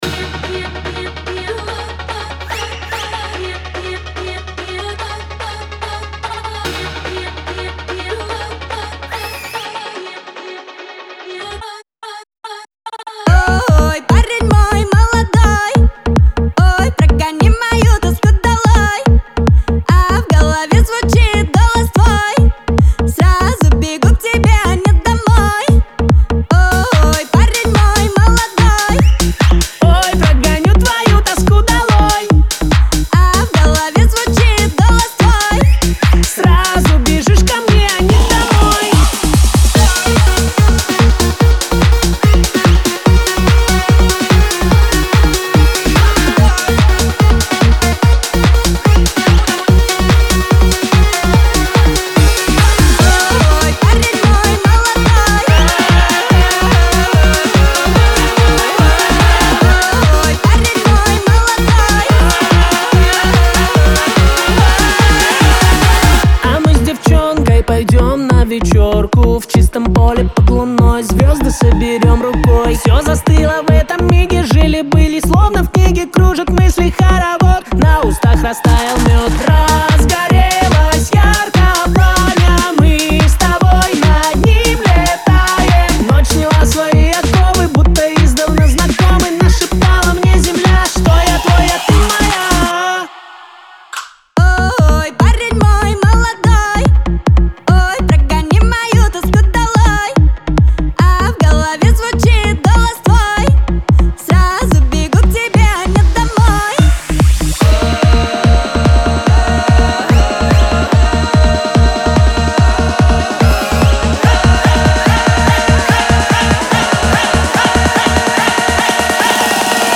pop
ансамбль